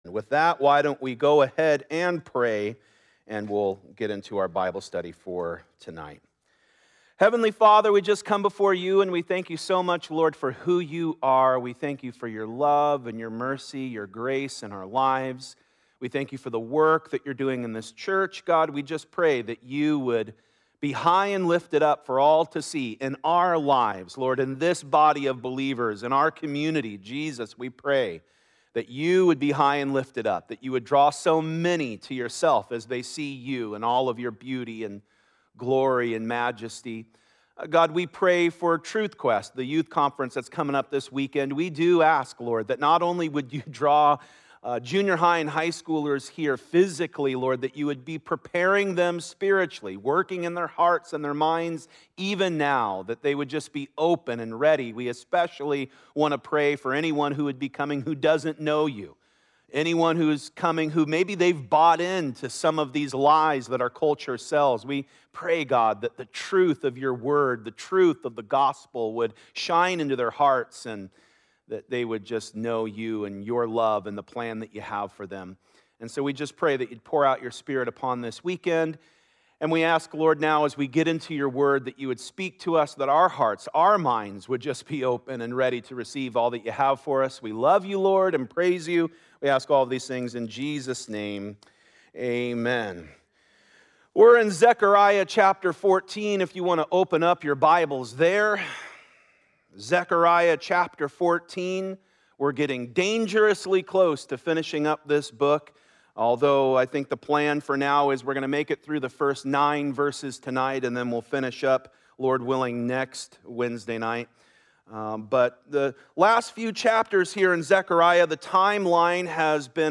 Sermon Audio